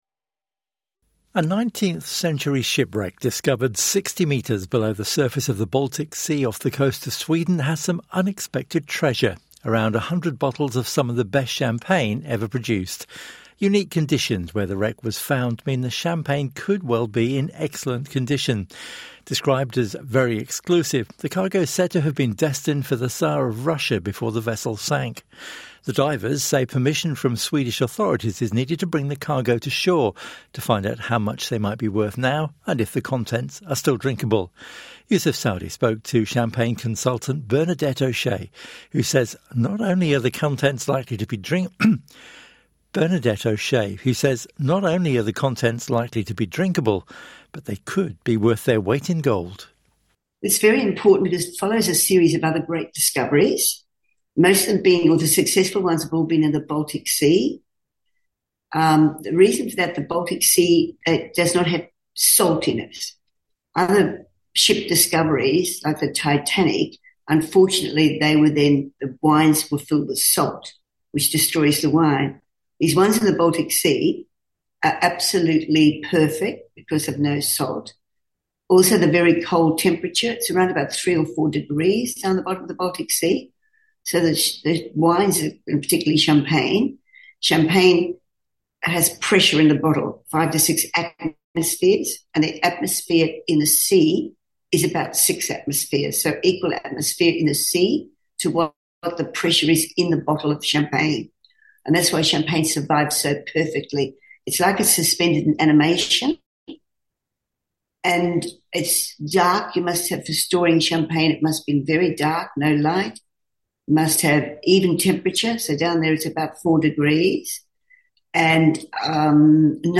INTERVIEW: Shipwreck champagne may still be fit for a tsar